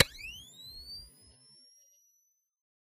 flash.ogg